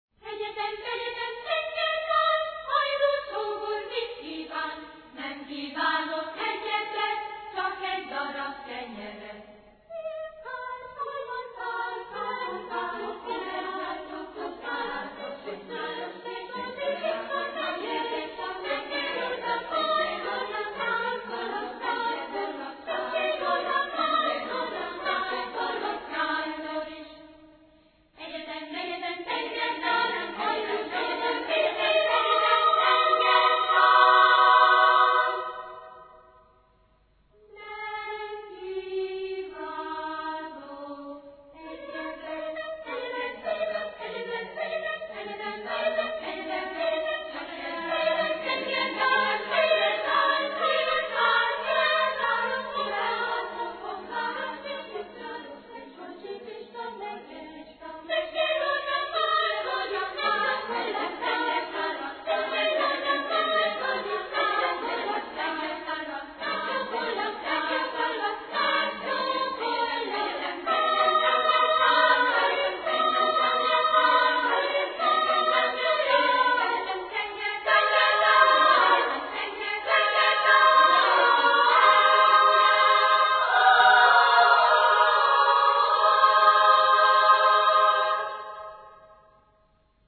Trio